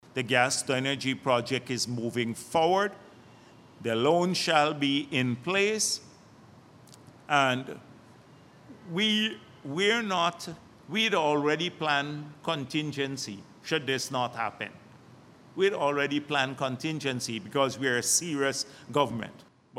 During a recent press conference at Freedom House, Dr. Jagdeo assured Guyanese that the Gas-to-Energy project is making significant strides.